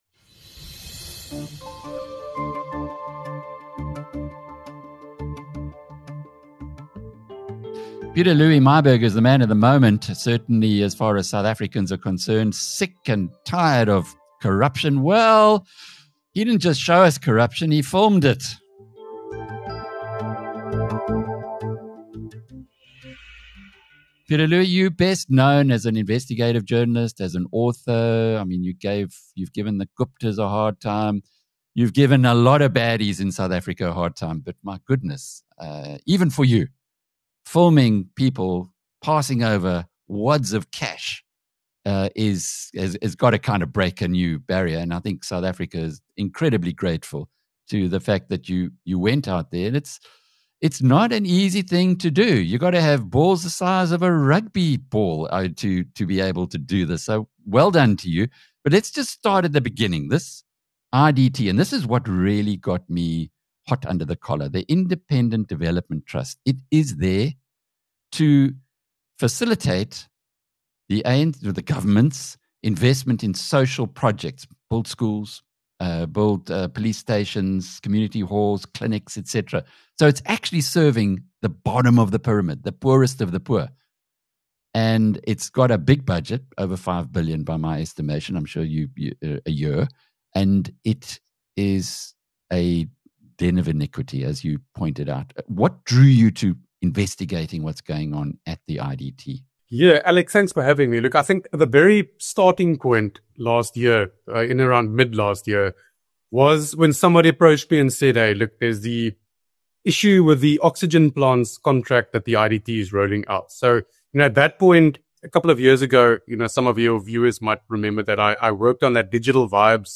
In an explosive interview